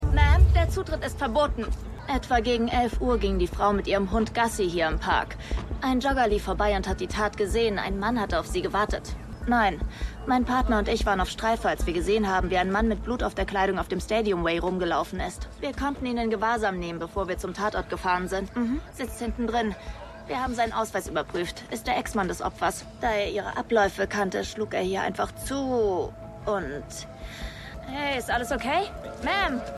Studio: Iyuno - Berlin - Germany
[NETFLIX | DUBBING]